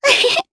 Rodina-Vox-Laugh_jp.wav